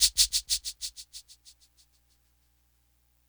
• Big Shaker One Shot D Key 48.wav
Royality free shaker sound tuned to the D note. Loudest frequency: 8810Hz
big-shaker-one-shot-d-key-48-xMn.wav